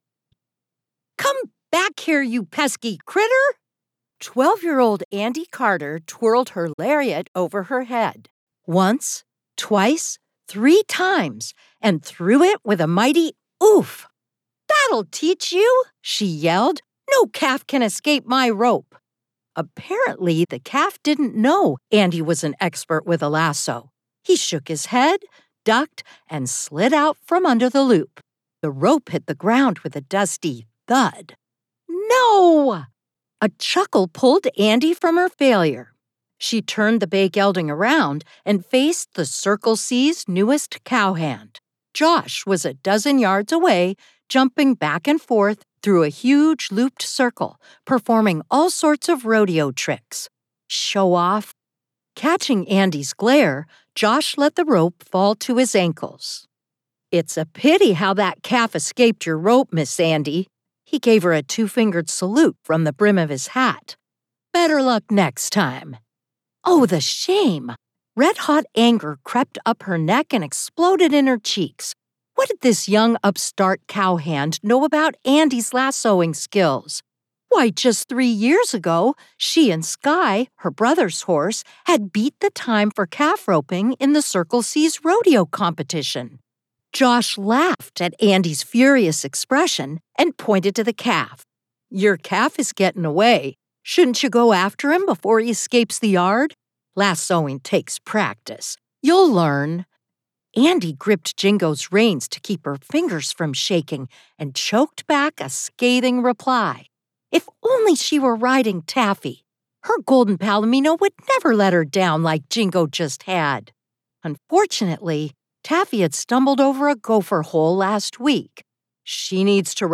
🎧 Audiobook Samples